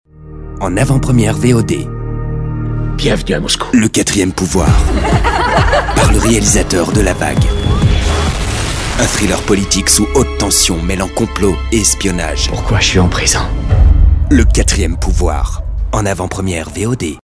Le timbre de ma voix est médium, jeune.
Sprechprobe: Sonstiges (Muttersprache):
french voice actor, medium voice